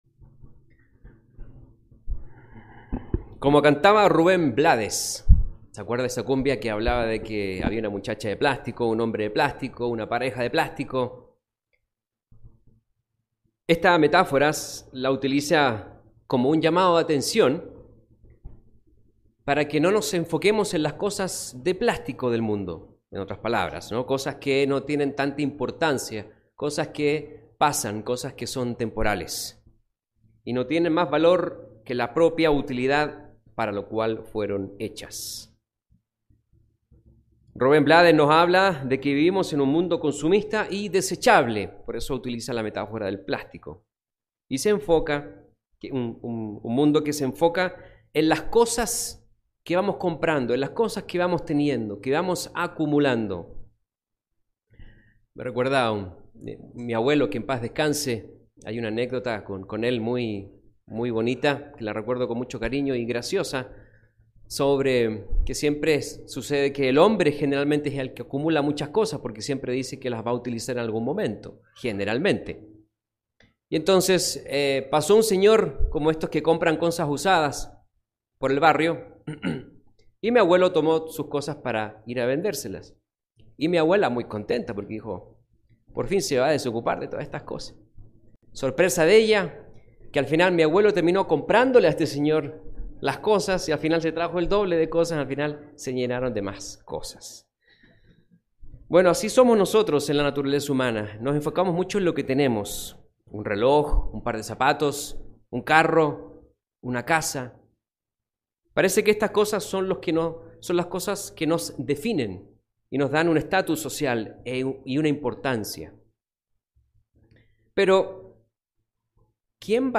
Mensaje entregado el 30 de abril de 2022.